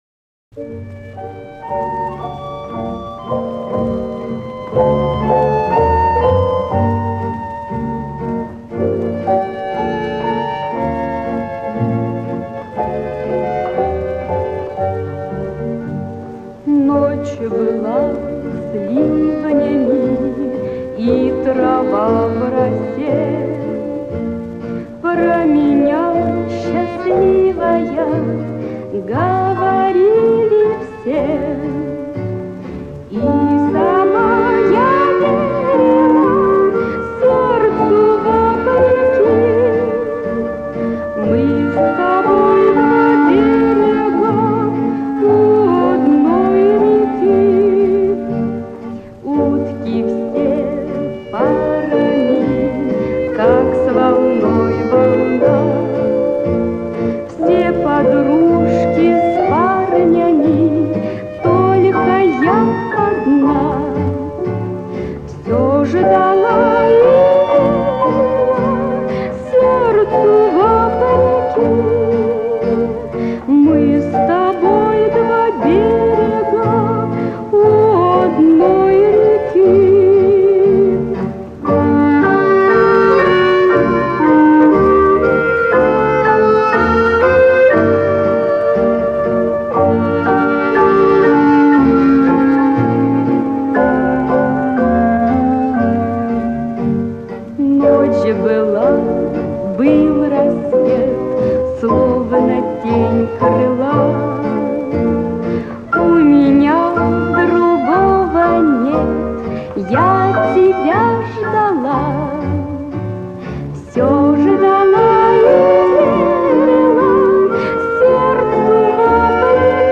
Спасибо большое за реставрацию песни, пост и информацию!